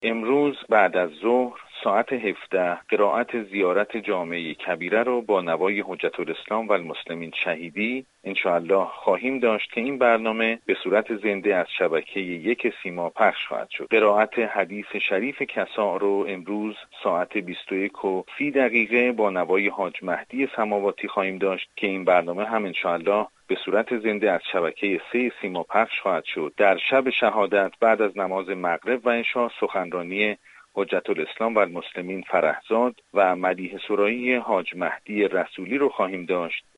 در گفتگو با خبر رادیو زیارت افزود: